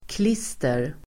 Uttal: [kl'is:ter]